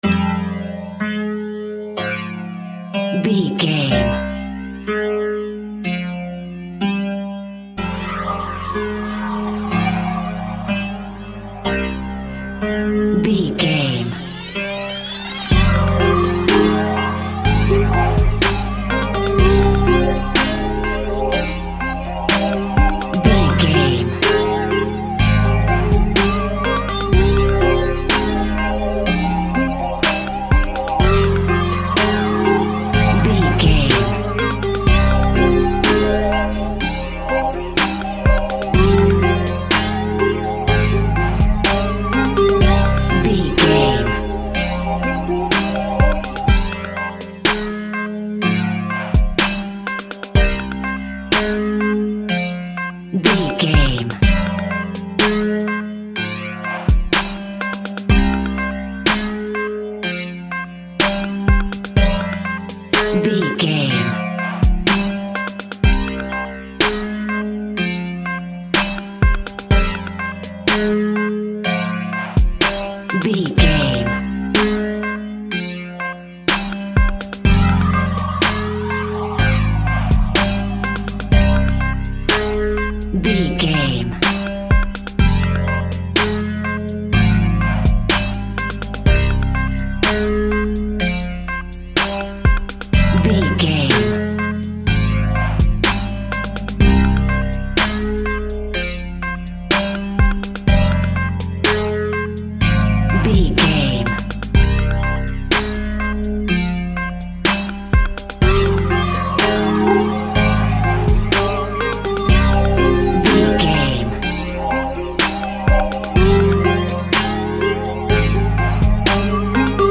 Aeolian/Minor
Slow
hip hop
chilled
laid back
groove
hip hop drums
hip hop synths
piano
hip hop pads